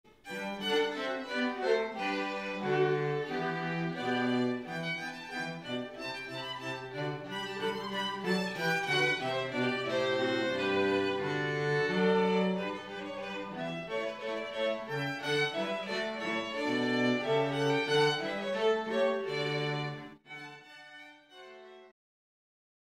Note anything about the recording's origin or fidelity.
We have made a home recording of some snippets of the most popular Processionals and Recessionals as an audio aid in making your selections.